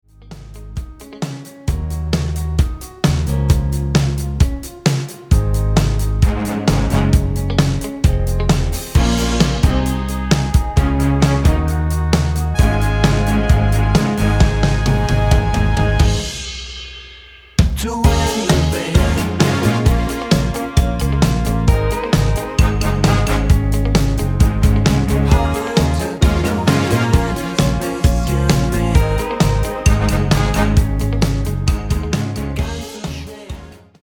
Mit Backing Vocals